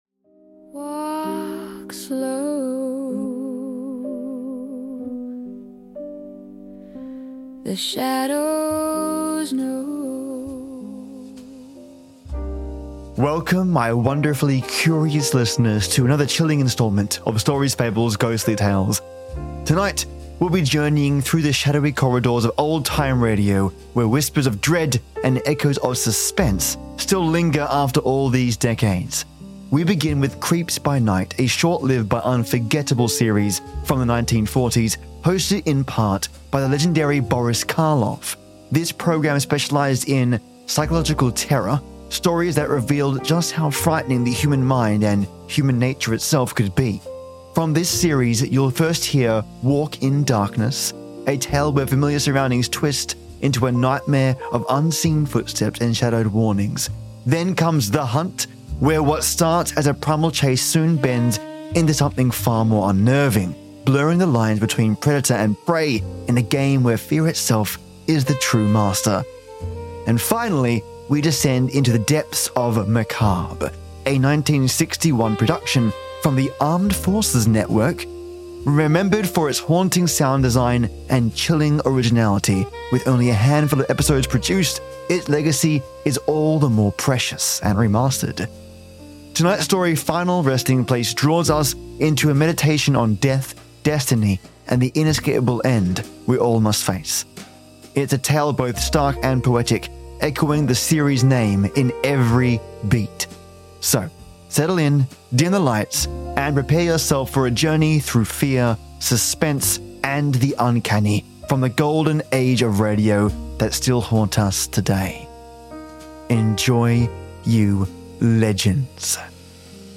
More about the Old Time Radio series 📻
A fusion of cinematic rock and ghostly orchestral energy, this piece is a tribute to the chilling brilliance of these old broadcasts.